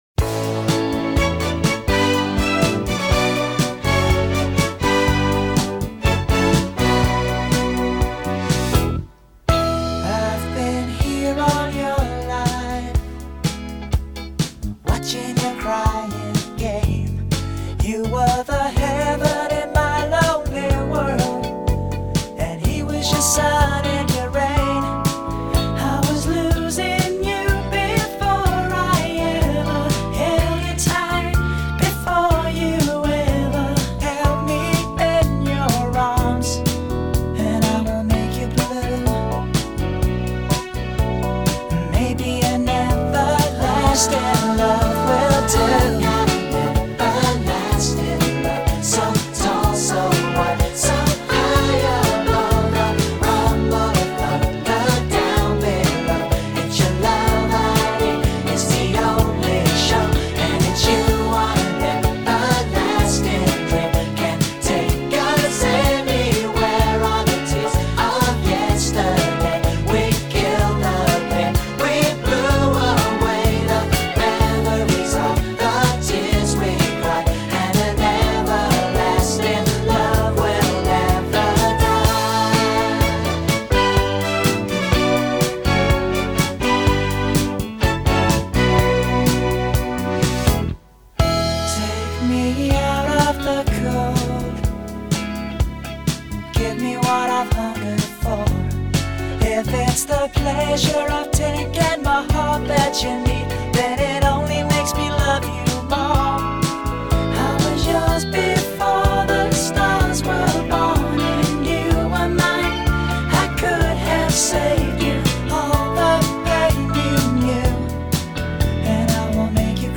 los falsetes